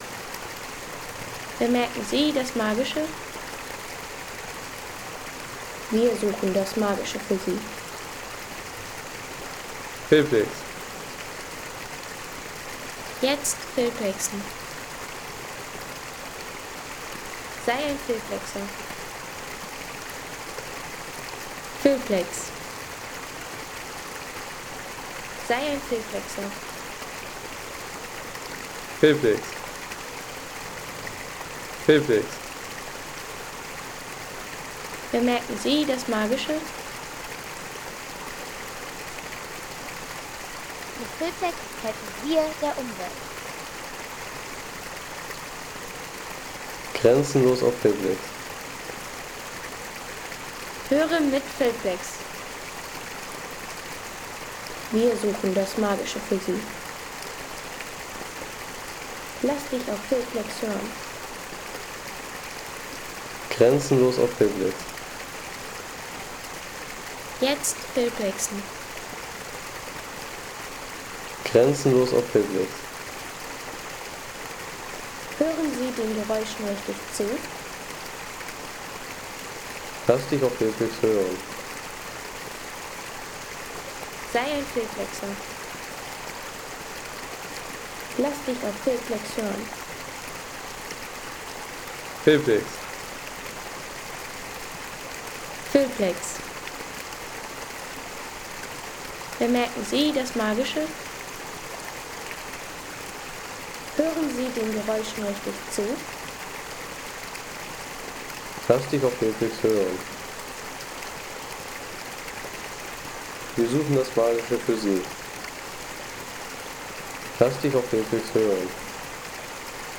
Wasserbach am Winkelbergsee
Landschaft - Bäche/Seen